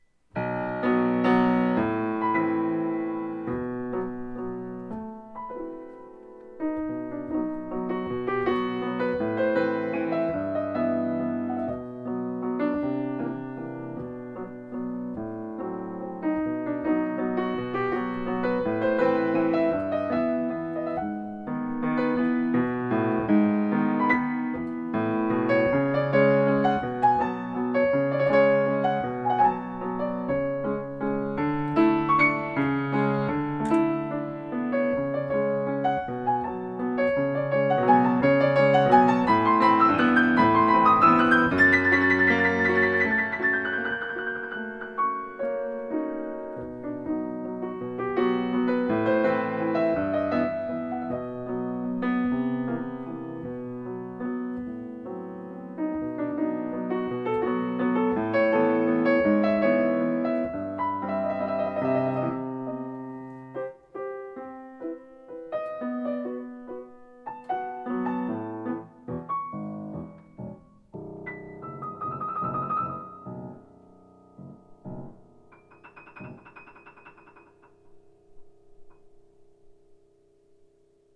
for Solo Piano
Social Dances is a suite of social dances for the piano.